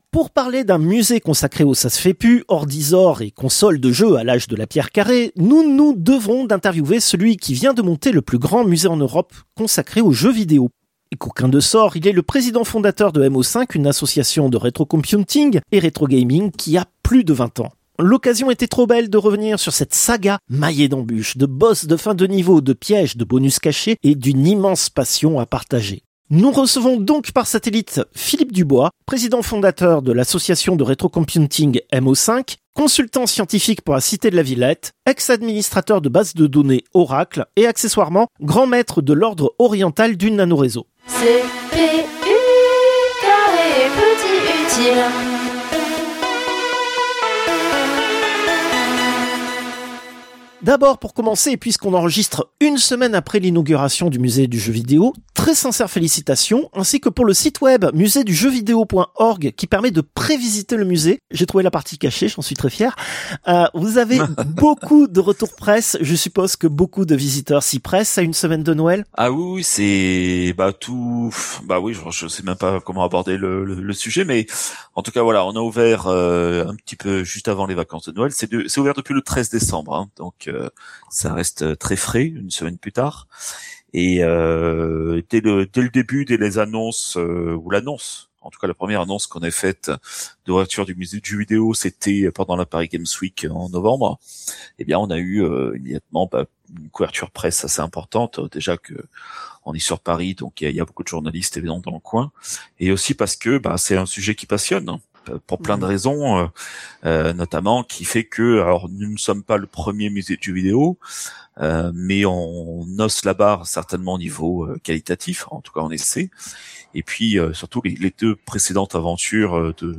Interviewes